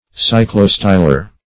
Search Result for " cyclostylar" : The Collaborative International Dictionary of English v.0.48: Cyclostylar \Cy`clo*sty"lar\ (s?`kl?-st?"?r), a. [Cyclo- + Gr. sty^los column.]
cyclostylar.mp3